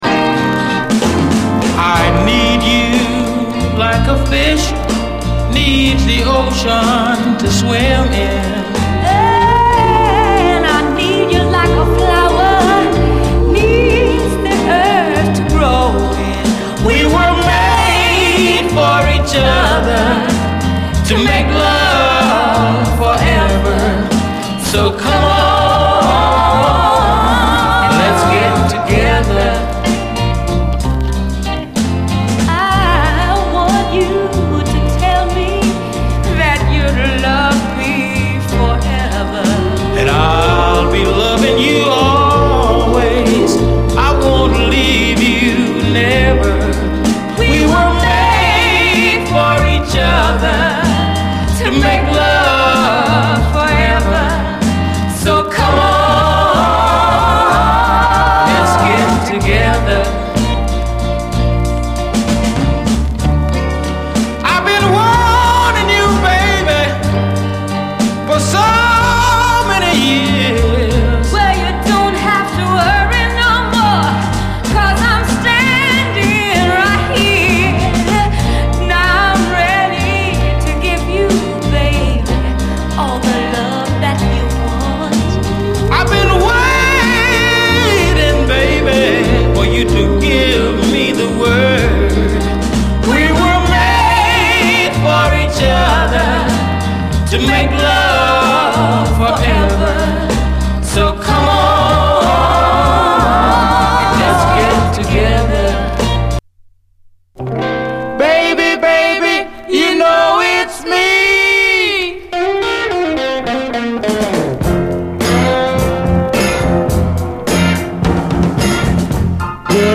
SOUL, 60's SOUL, 7INCH
オルガンにも哀愁が滲む、泣きのスウィート・クロスオーヴァー・ソウル！